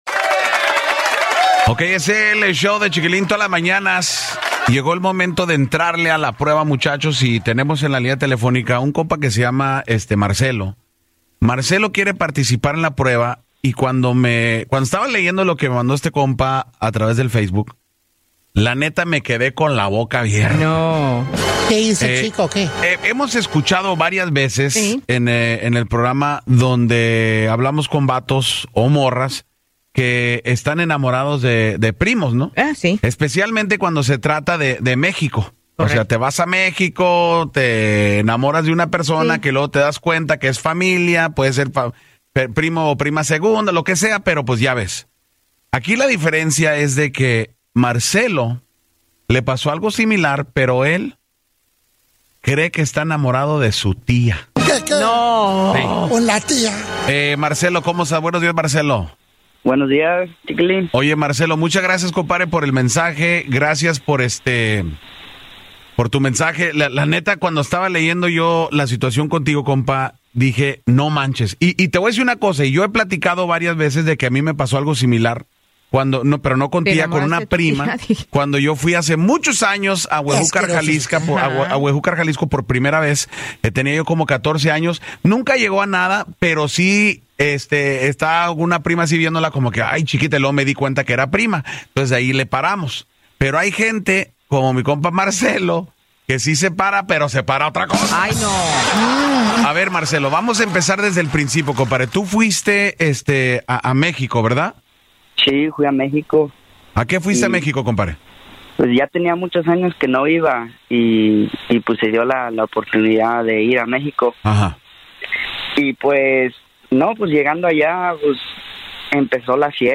un joven que buscaba ayuda para encontrar una solución a su conflicto amoroso.